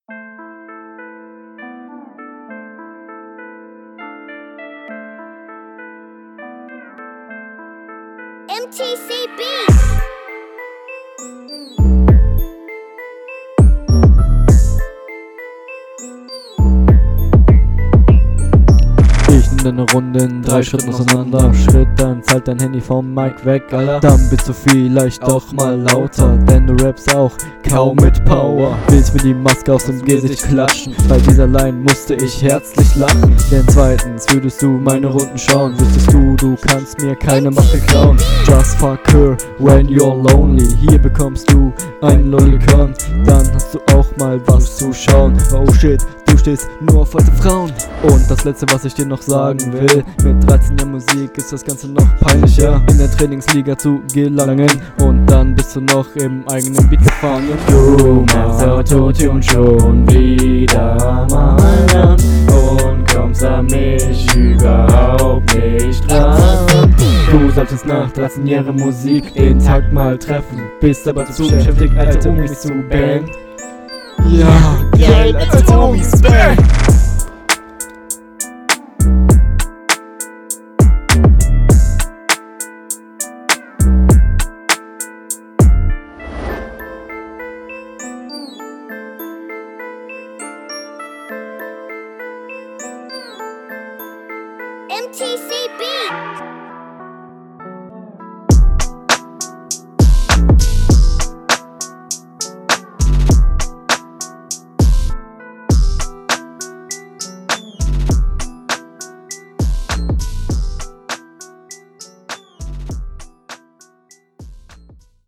Man versteht dich besser.